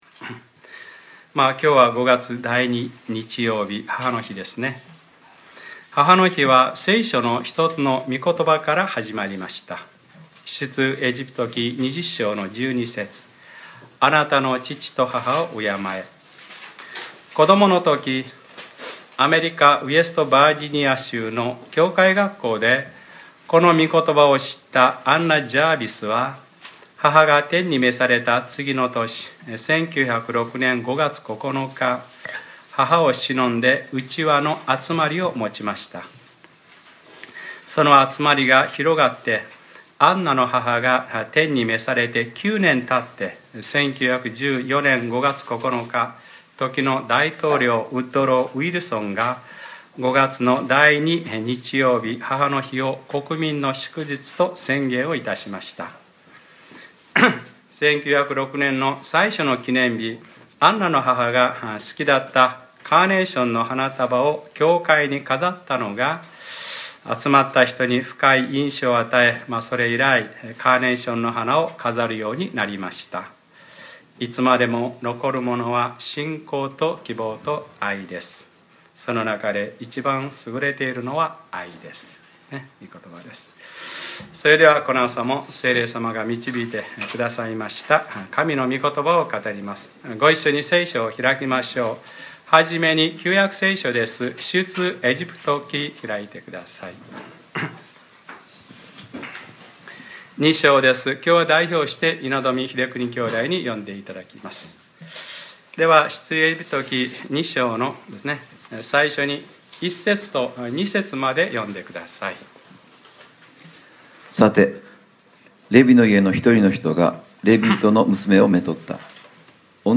2014年 5月11日（日）礼拝説教『母の日：モーセの母ヨケベデ』